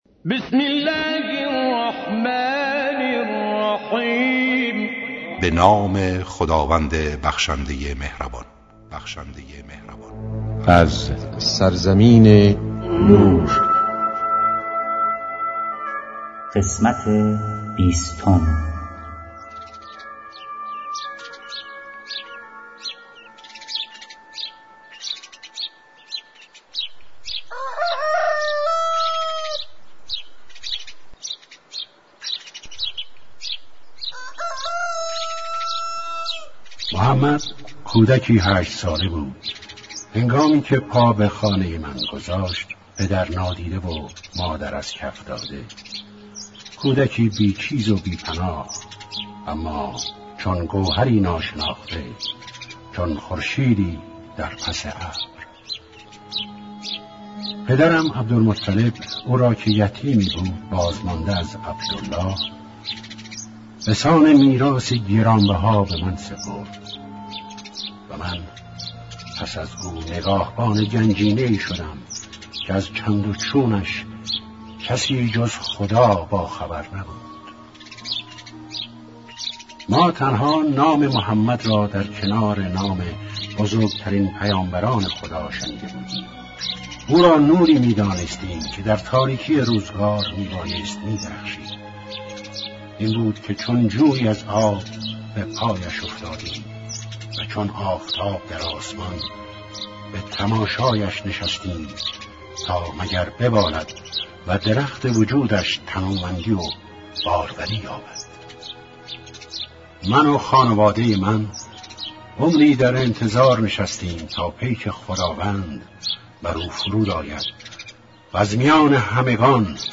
با اجرای مشهورترین صداپیشگان، با اصلاح و صداگذاری جدید
کتاب گویا, کیفیت بالا